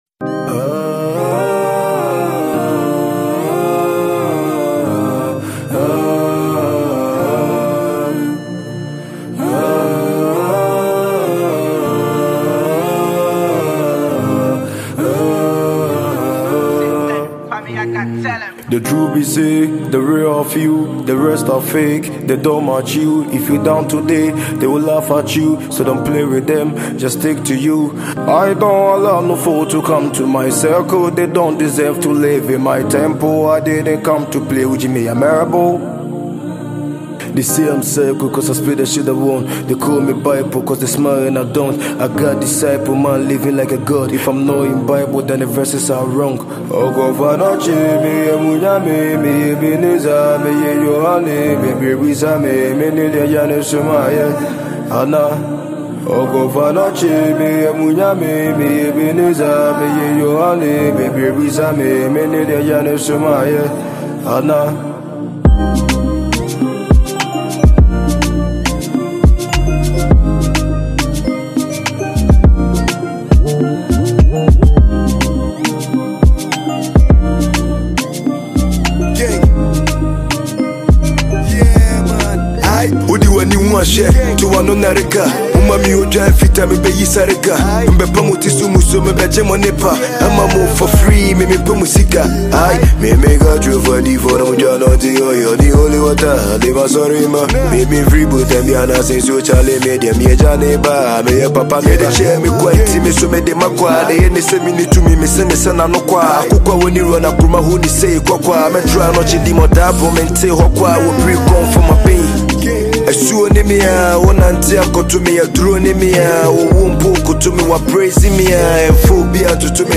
Multi-talented Ghanaian rapper and songwriter